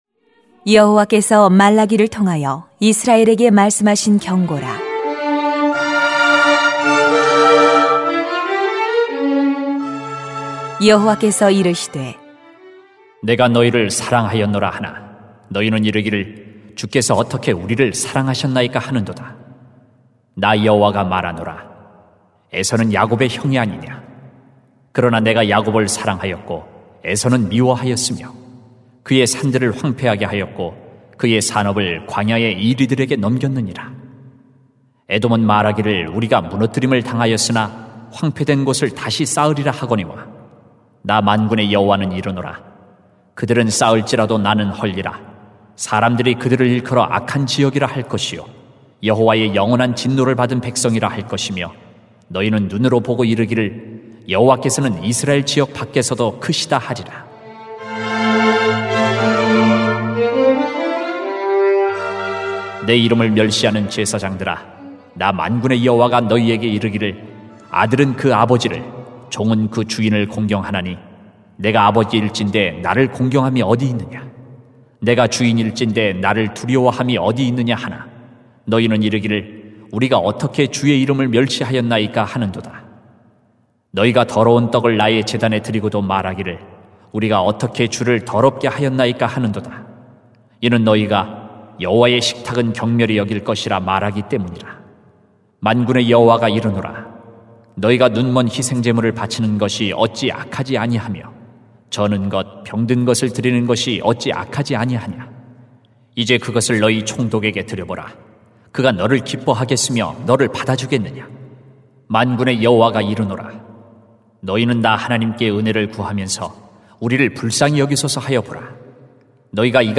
[말 1:1-14] 내가 너희를 사랑하였노라 > 새벽기도회 | 전주제자교회